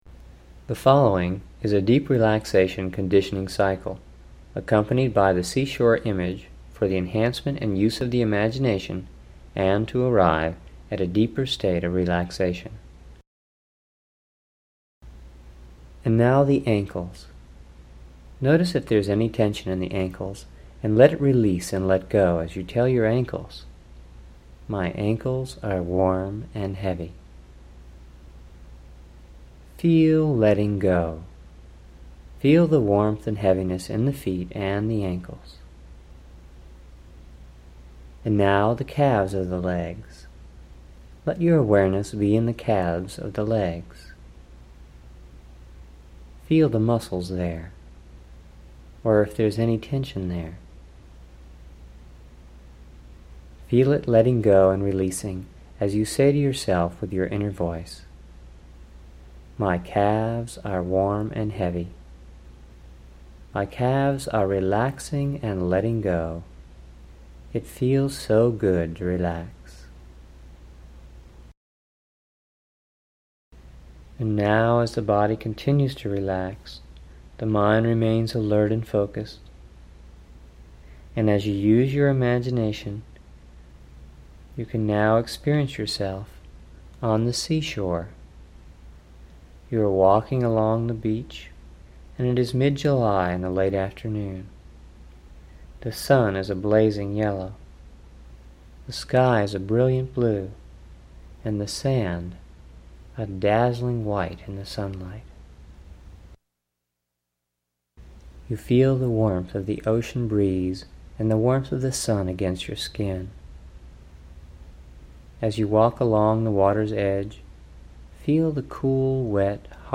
You will love this profoundly effective guided relaxation tool that also has imagery of the sea shore for rejuvination.